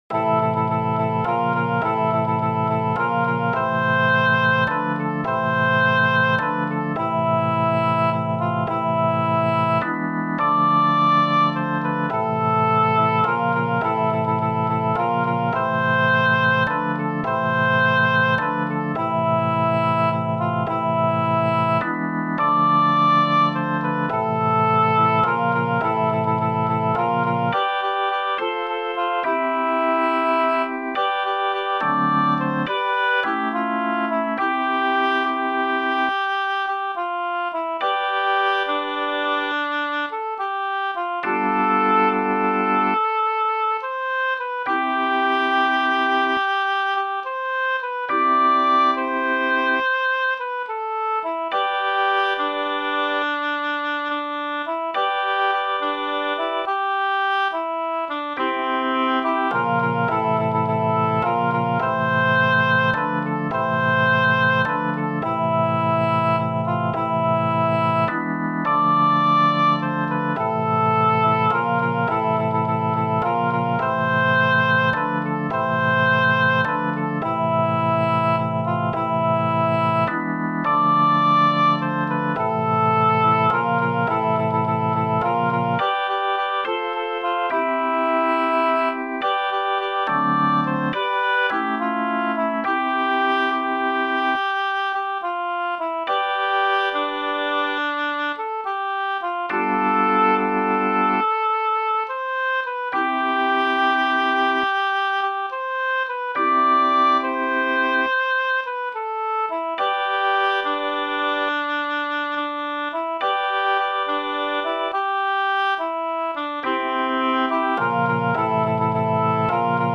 instrumentaal